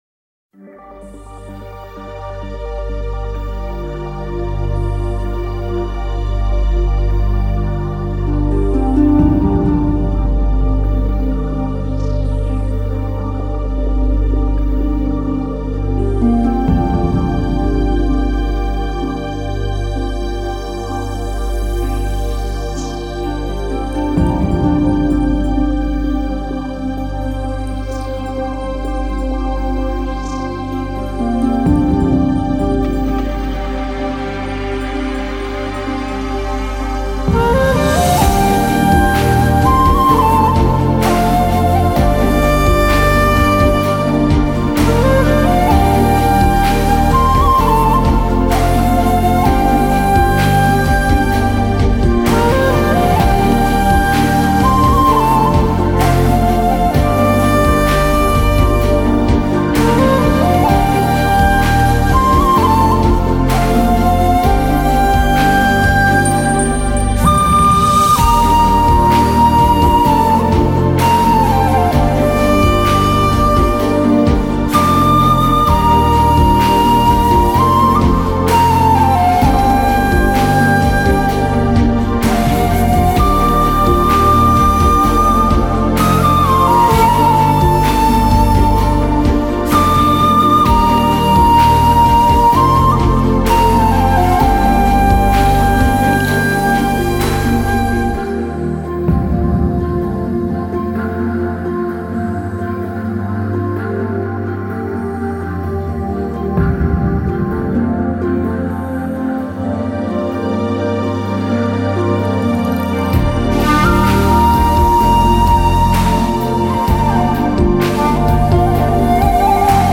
アンビエントなニューエイジ・サウンドから、アグレッシヴなダンス・ビートまで。